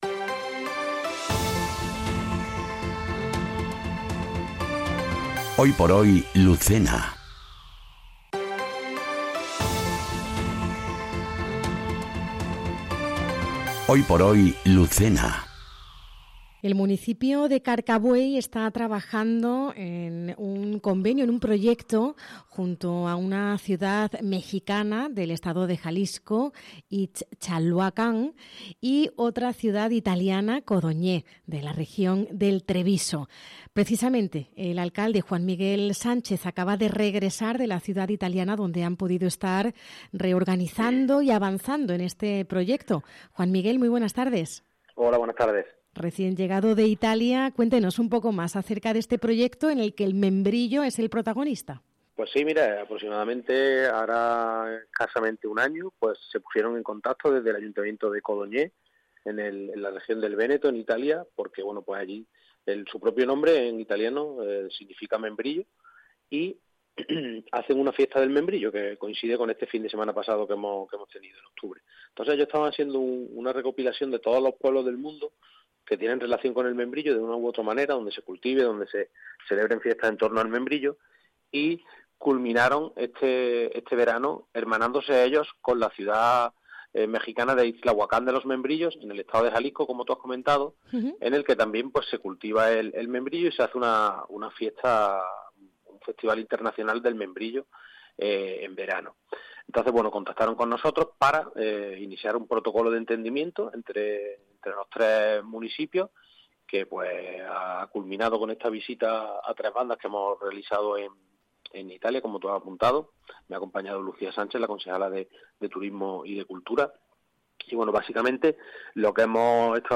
ENTREVISTA | Juan Miguel Sánchez, alcalde de Carcabuey - Andalucía Centro